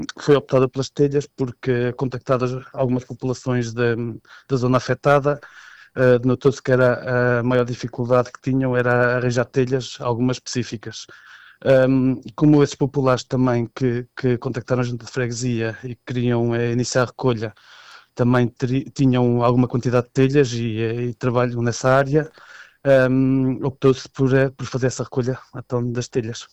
O autarca detalha ainda o tipo de ajuda que está a ser recolhida e justifica a aposta específica nas telhas, um dos materiais mais urgentes para a recuperação das habitações danificadas: